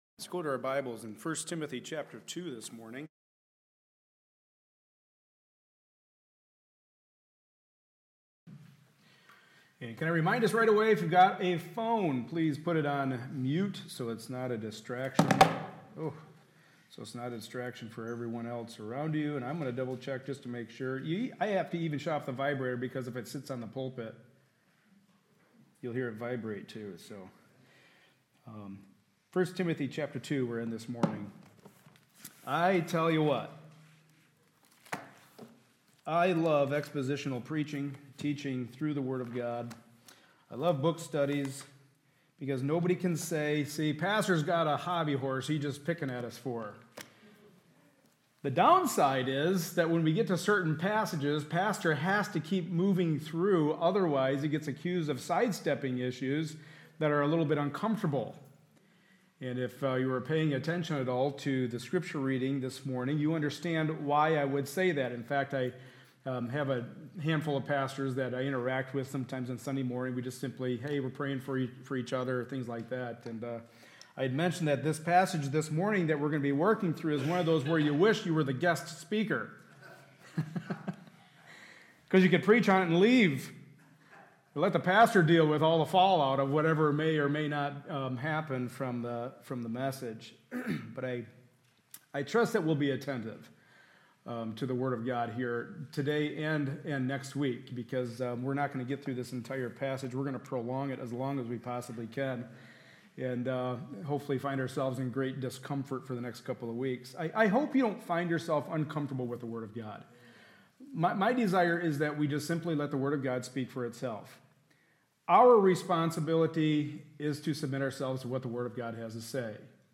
Passage: 1 Timothy 2:8-15 Service Type: Sunday Morning Service